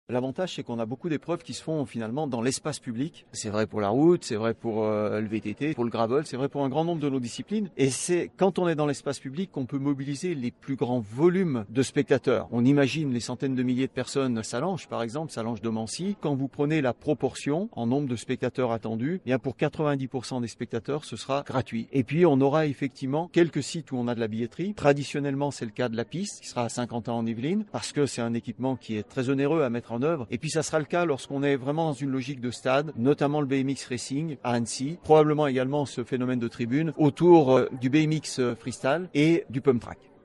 Les principaux acteurs de l’organisation, les élus ainsi que de nombreux athlètes se sont réunis, ce jeudi 23 avril 2026, au Congrès Impérial, à Annecy, pour présenter les contours de cet évènement spectaculaire. 14 sites ont été retenus et confirmés sur le département, avec le vélodrome de Saint-Quentin-en-Yvelines.